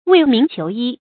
未明求衣 注音： ㄨㄟˋ ㄇㄧㄥˊ ㄑㄧㄡˊ ㄧ 讀音讀法： 意思解釋： 天沒有亮就穿衣起床。形容勤于政事。